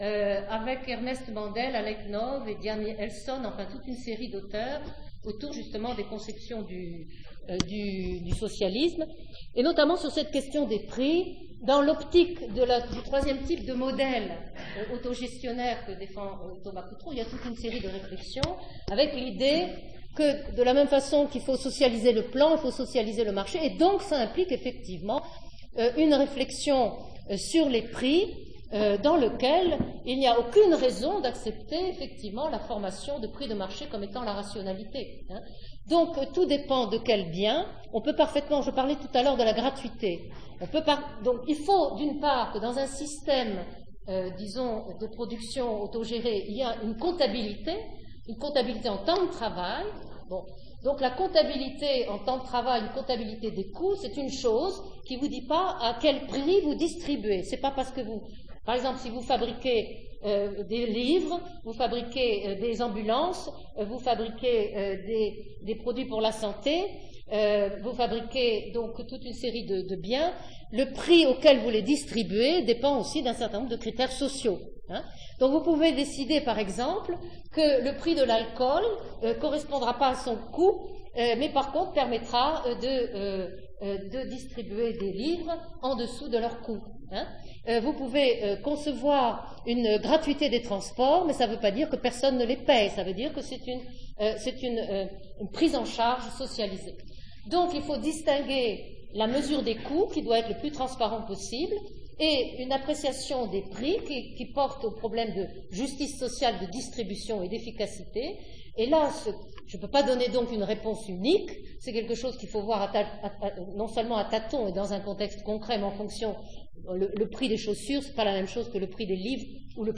Conférence réalisée dans le cadre du séminaire « Marx au XXIe siècle ».